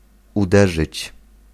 Ääntäminen
IPA: [batʁ]